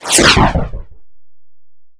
fireshield.wav